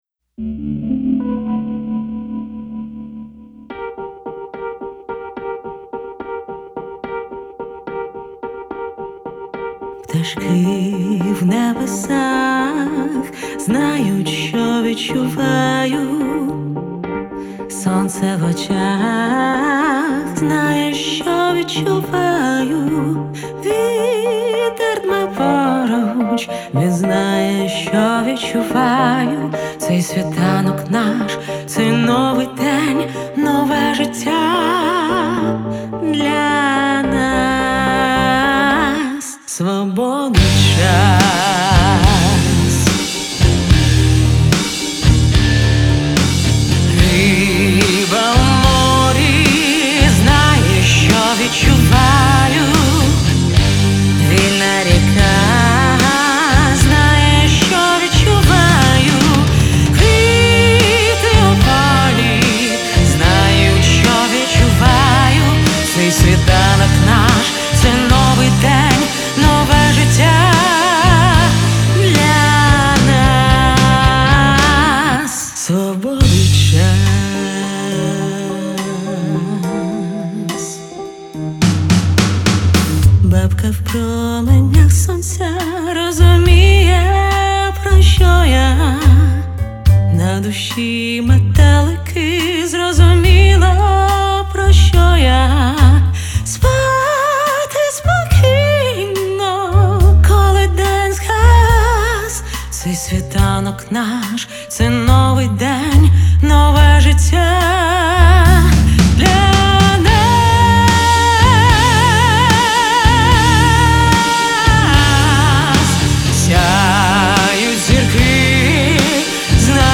Переклад, рима, редакція та спів